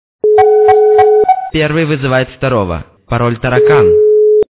» Звуки » Люди фразы » Первый вызывает воторого! - Пароль Таракан
При прослушивании Первый вызывает воторого! - Пароль Таракан качество понижено и присутствуют гудки.